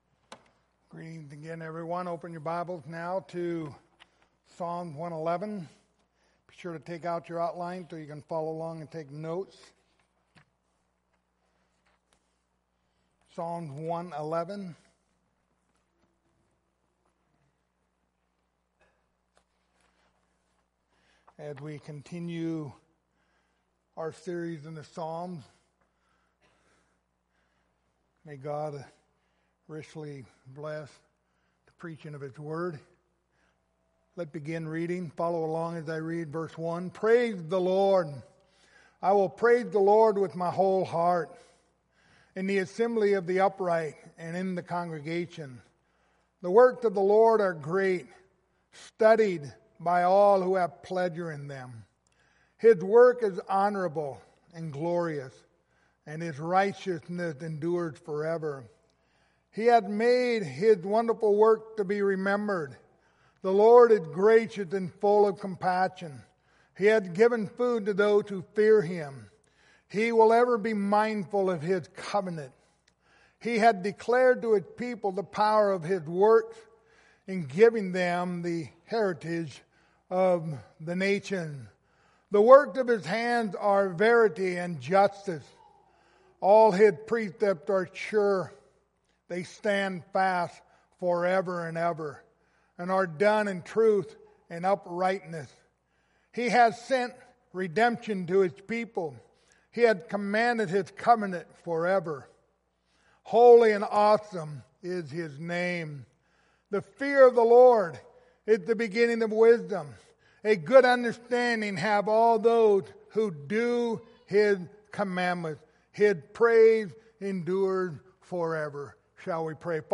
The book of Psalms Passage: Psalms 111 Service Type: Sunday Morning Topics